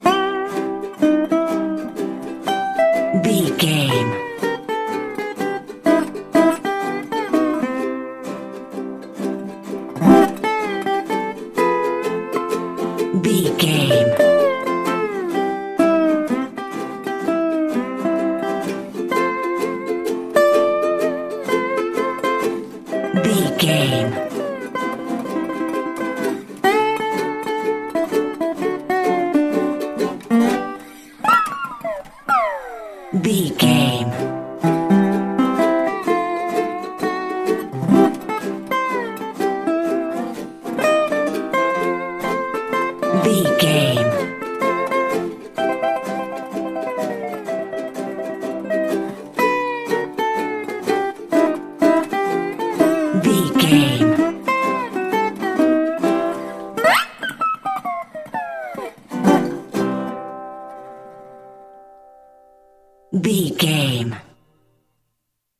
Ionian/Major
acoustic guitar
percussion
ukulele